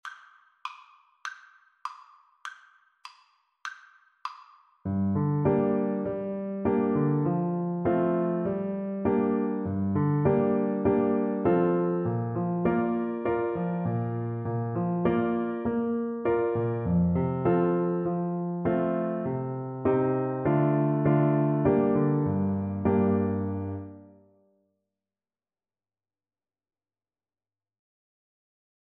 Play (or use space bar on your keyboard) Pause Music Playalong - Piano Accompaniment Playalong Band Accompaniment not yet available transpose reset tempo print settings full screen
Moderato
G minor (Sounding Pitch) A minor (Clarinet in Bb) (View more G minor Music for Clarinet )
Traditional (View more Traditional Clarinet Music)